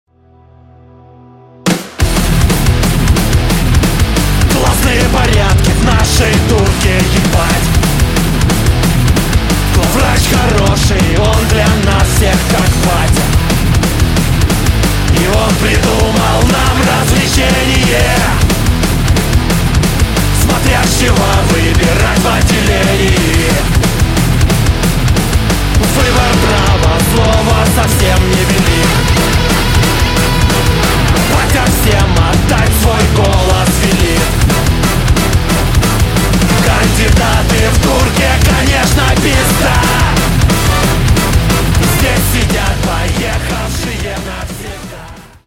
громкие
сумасшедшие
ска-панк
цикличные